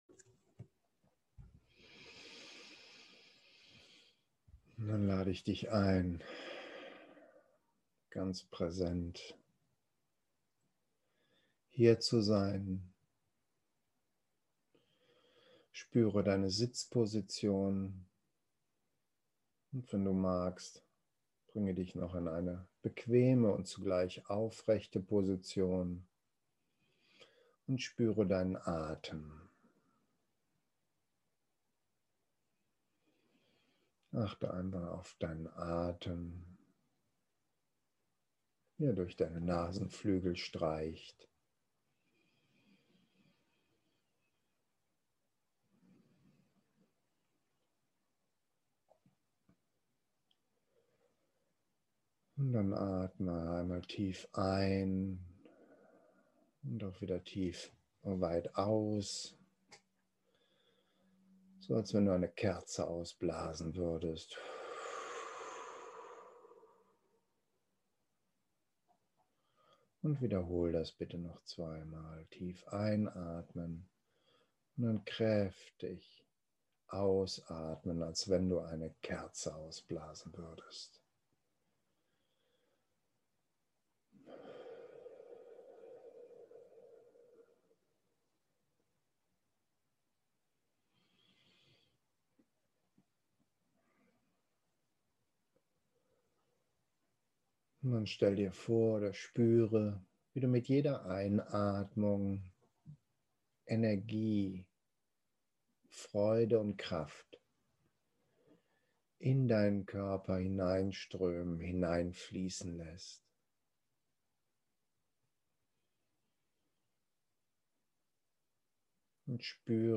Unser Geschenk für dich: Mitgefühls – Wertschätzungsmeditation Wir laden dich ein, dir dazu einen ruhigen Raum zu nehmen.